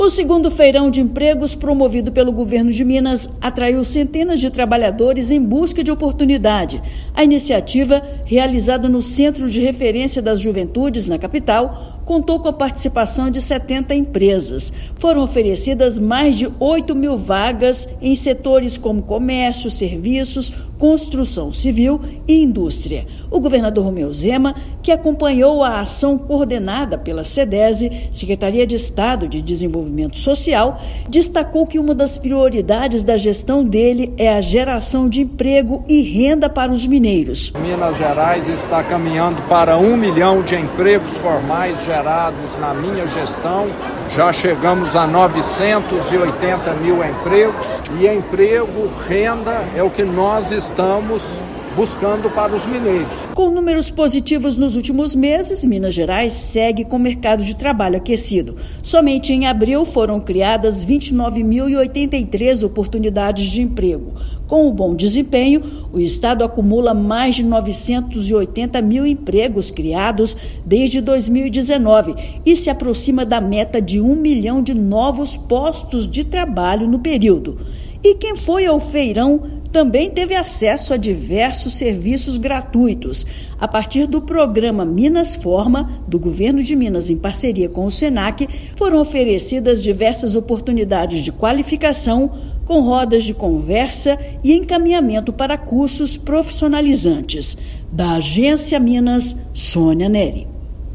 Iniciativa da Sedese acelera mercado, cada vez mais perto da meta de 1 milhão de empregos no estado. Ouça matéria de rádio.